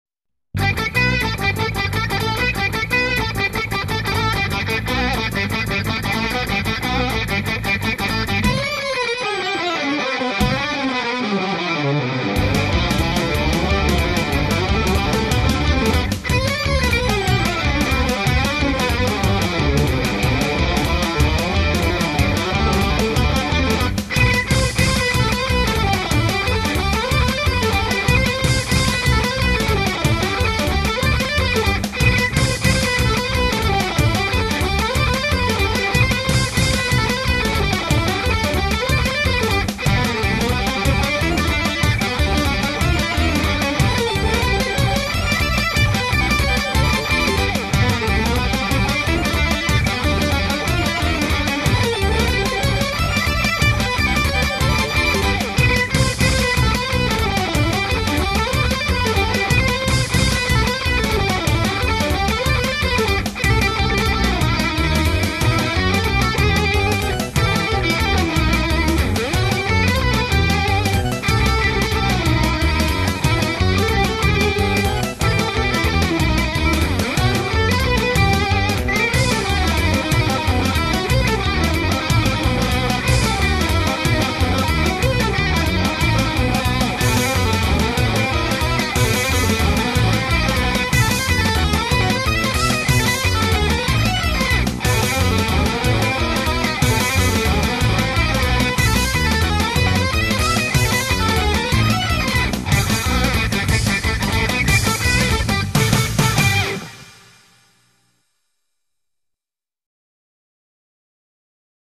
Macedonia, trad.: 11/16